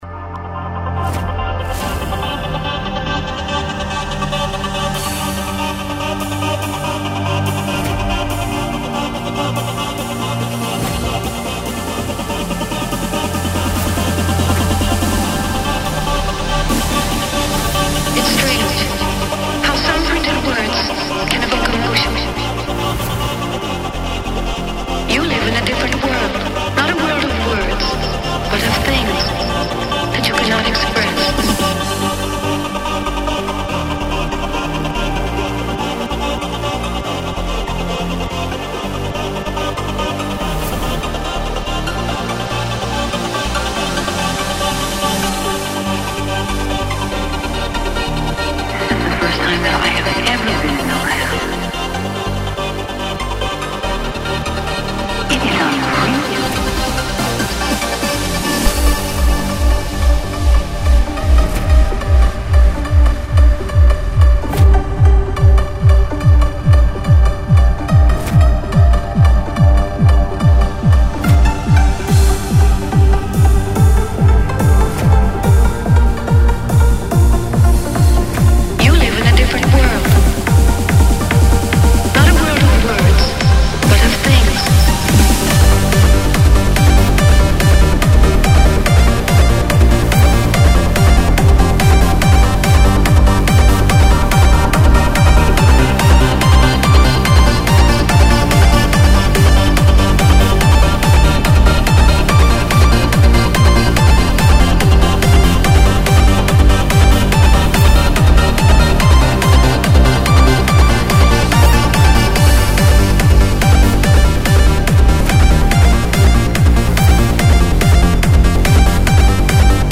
Previews (trimmed):